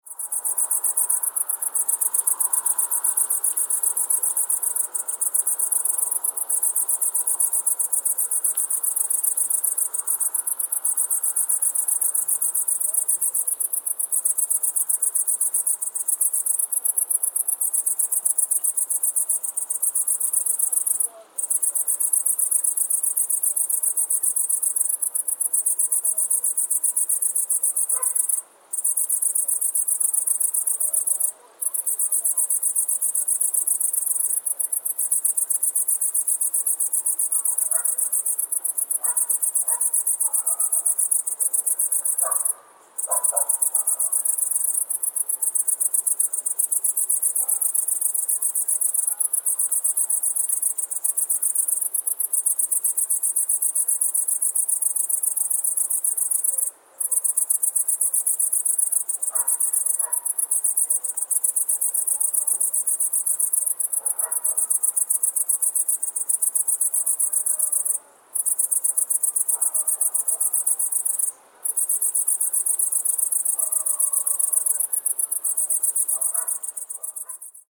Relaxing Sounds / Sound Effects 20 Jan, 2026 Summer Night Insects Chirping In Yard Sound Effect Read more & Download...
Summer-night-insects-chirping-in-yard-sound-effect.mp3